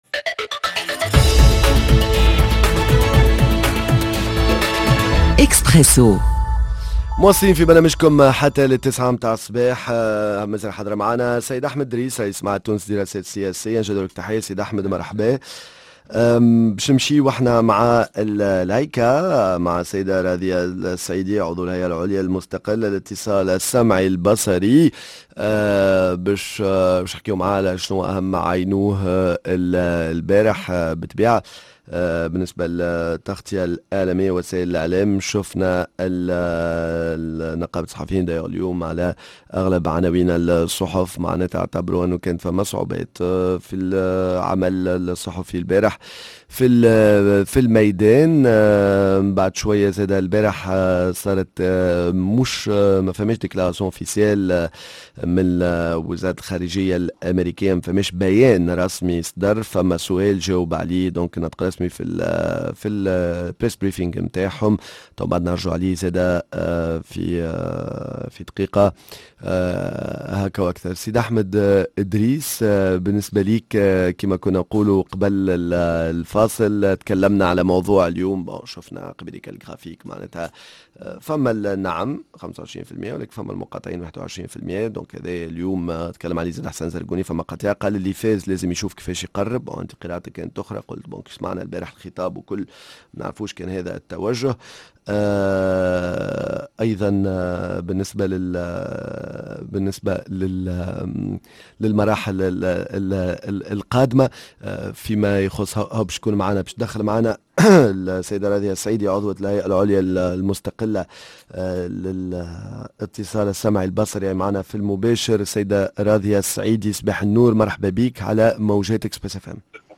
الهيئة العليا المستقلة للاتصال السمعي البصري رصدت مجموعة من الاخلالات تتعلق بالصمت الانتخابي، ضيفتنا عضو الهيئة راضية السعيدي